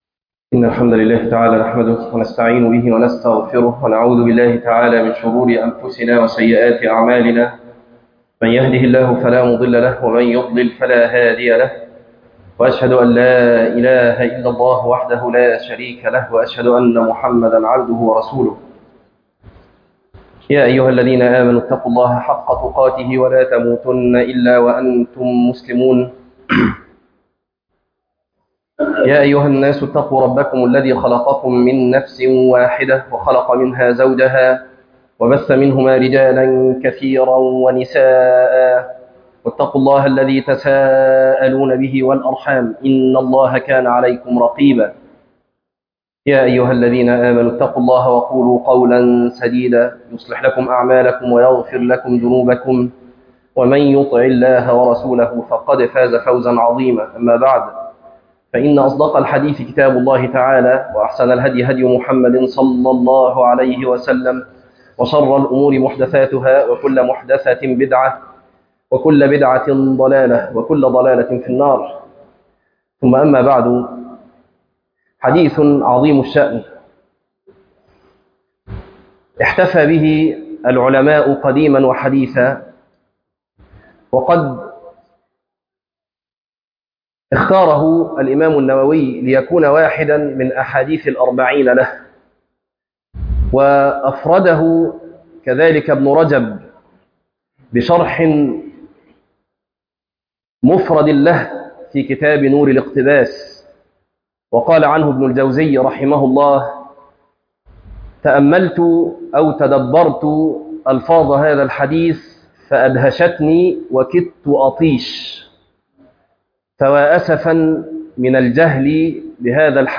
تفاصيل المادة عنوان المادة ١- يا غلام إني أعلمك كلمات - خطبة تاريخ التحميل الأثنين 13 ابريل 2026 مـ حجم المادة غير معروف عدد الزيارات 17 زيارة عدد مرات الحفظ 7 مرة إستماع المادة حفظ المادة اضف تعليقك أرسل لصديق